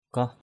/kɔh/ 1.